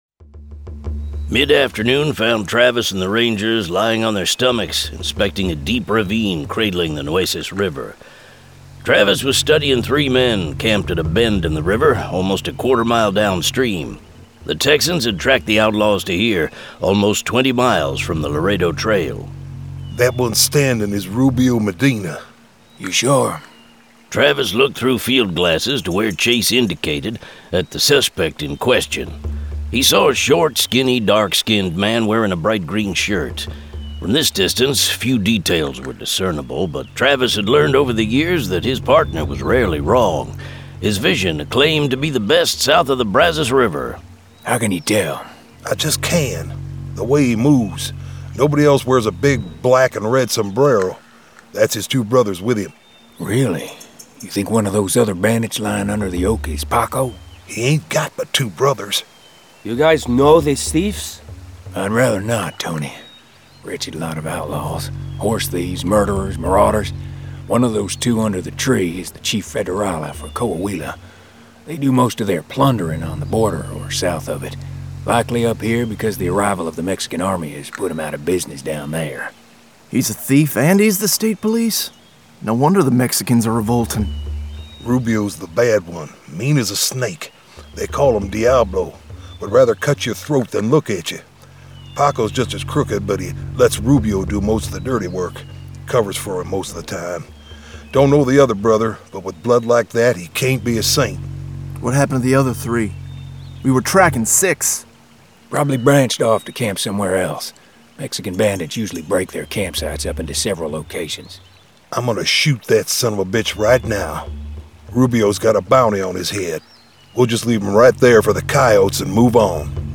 Full Cast. Cinematic Music. Sound Effects.
Genre: Western